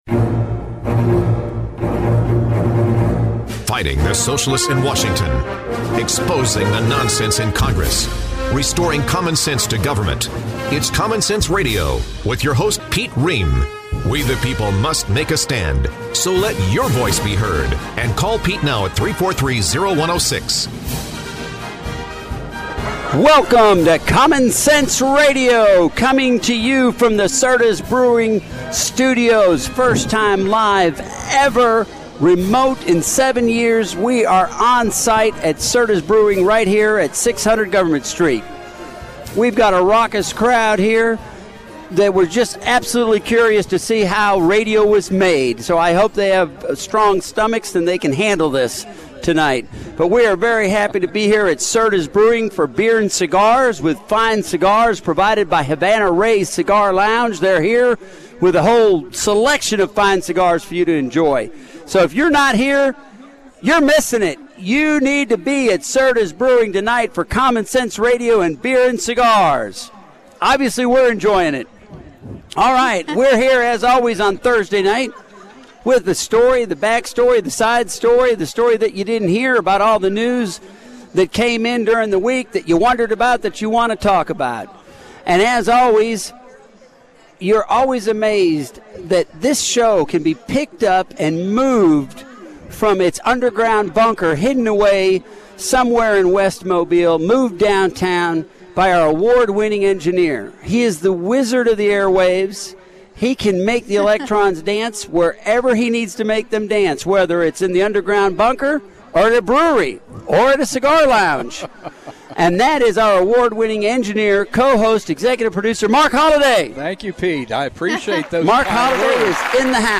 on location
conversation